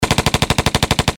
без слов
выстрелы
Звук стрельбы из автомата